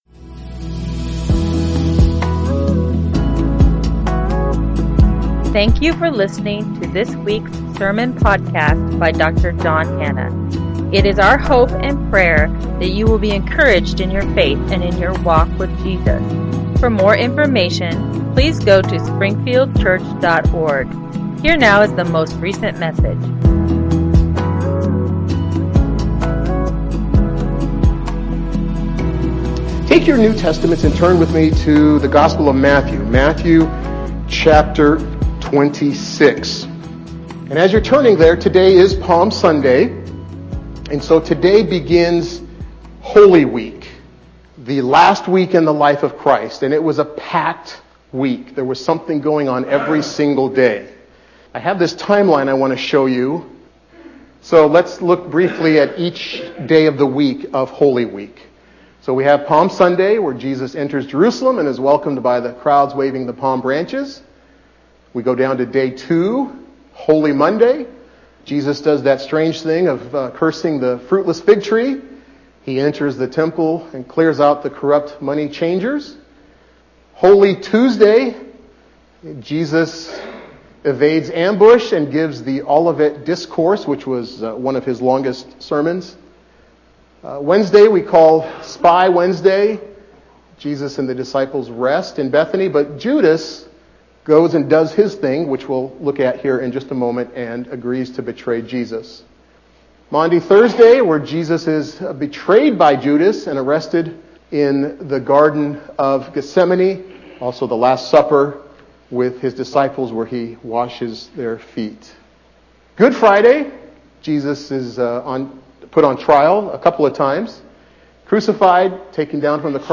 I Am Judas” Preacher